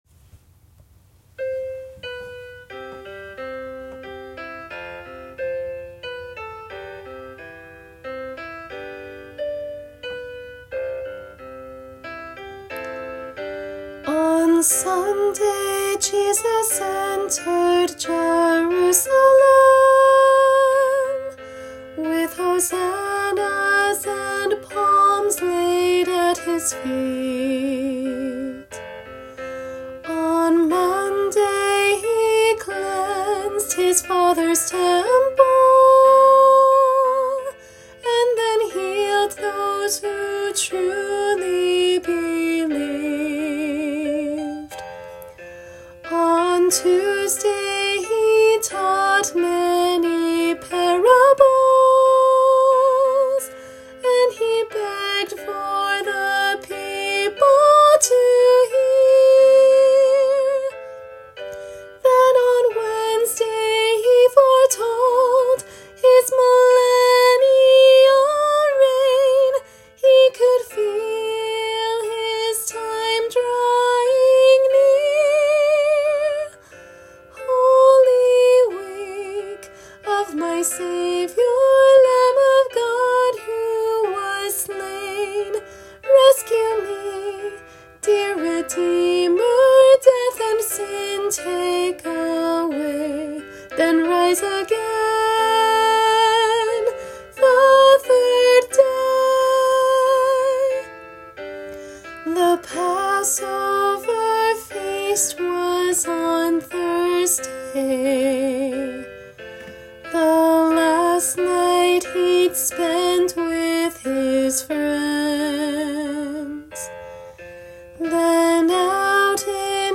Vocal Solo/Ensemble